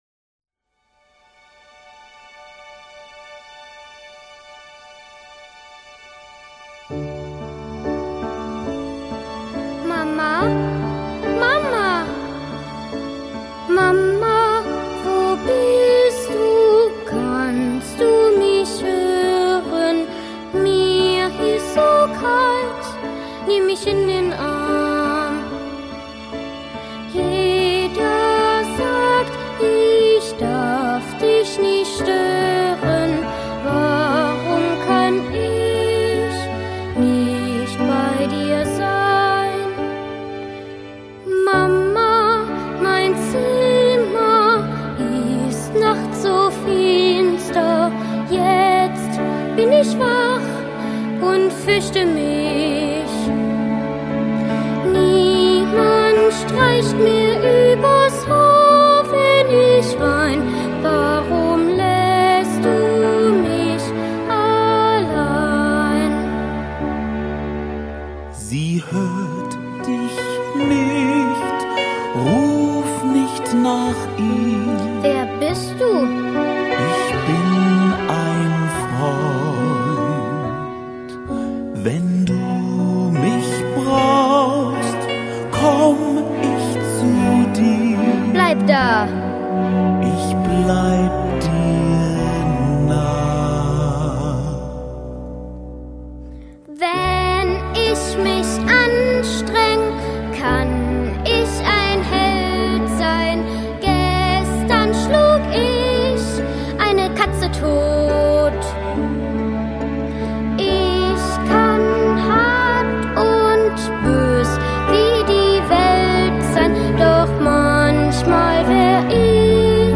дуэт дер Тода и маленького Рудольфа